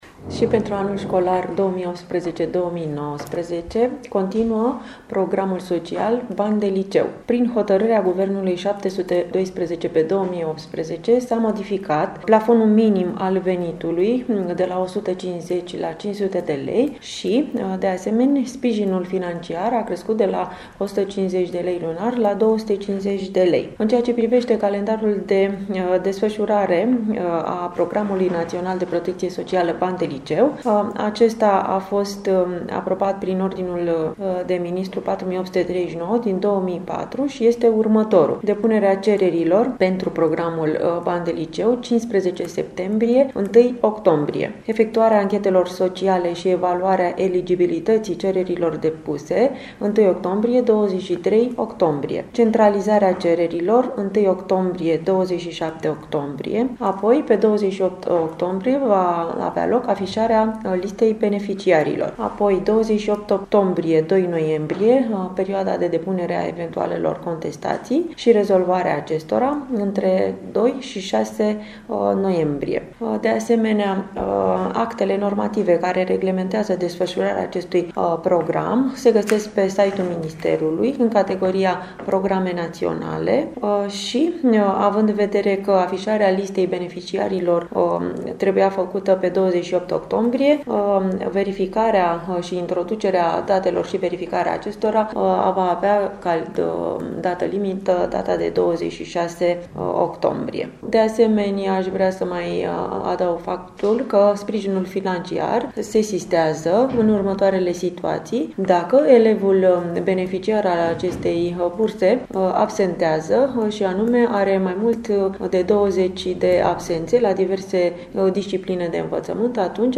Inspector general din cadrul I.S.J. Dolj – prof. Monica Sună